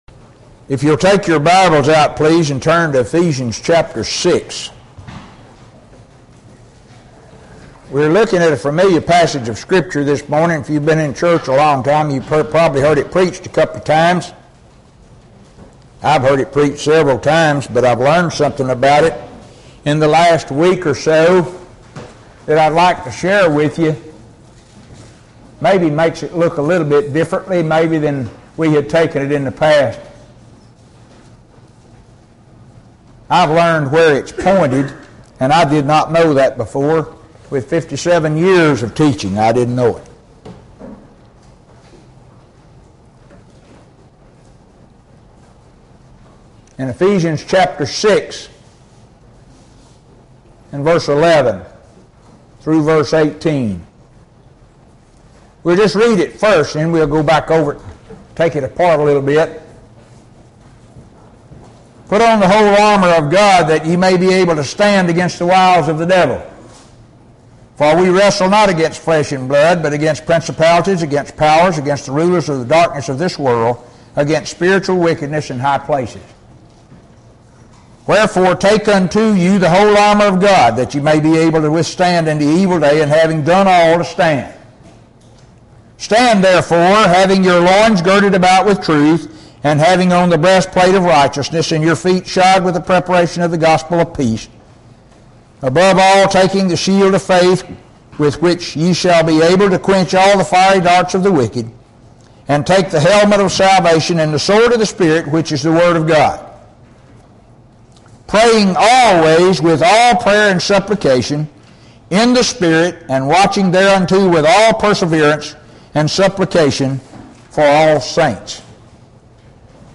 posted in Sermon